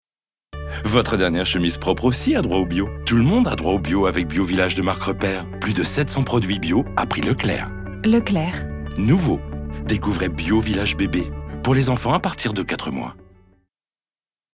Démo pub produits bio
45 - 65 ans - Basse